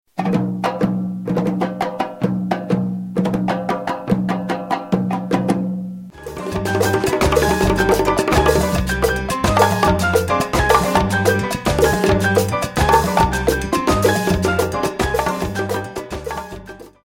Here I aim to share with you my experiences providing Afro-Caribbean, Latin Jazz, and Salsa music to the San Diego and Southern California communities for many years.
medley2.mp3